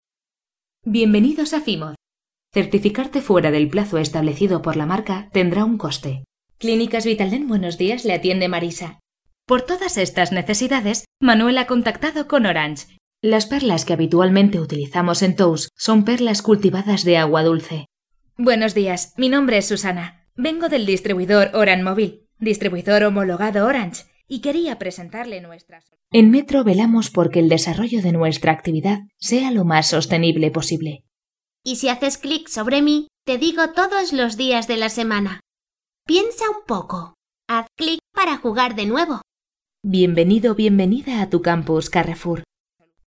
Voz media cálida con registros de tonos altos y timbrada para locuciones y narraciones.
Soprano.
kastilisch
Sprechprobe: eLearning (Muttersprache):